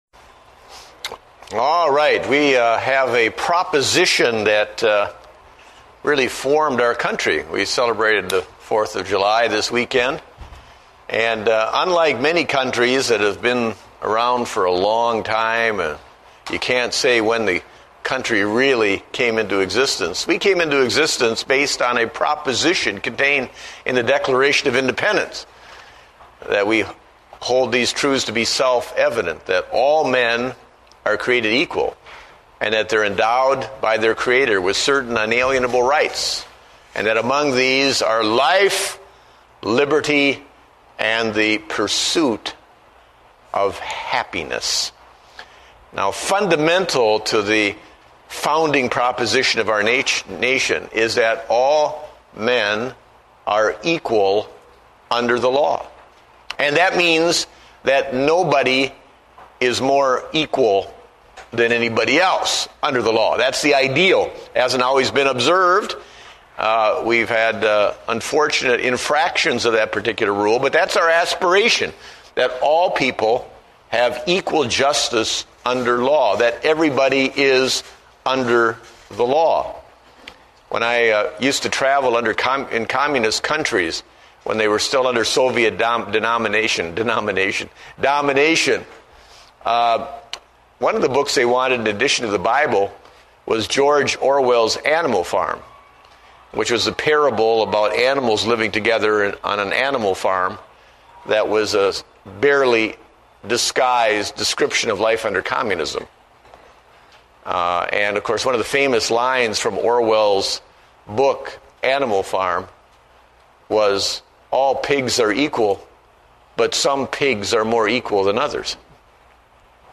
Date: July 6, 2008 (Adult Sunday School)